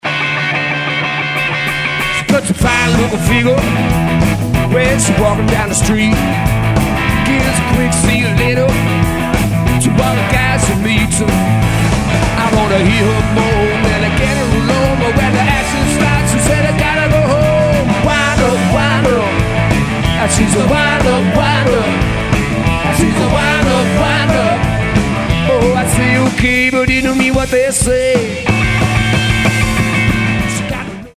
exemple 1 : feel1 : c'est simplement une prise live en sortie stéréo de ma console Soundcraft pendant le concert; la guitare n'est pris qu'avec un seul micro (BF509) ! mais l'ensemble du mix est géré (pour le concert live) avec quelques delays et quelques reverbes plus ou moins dosé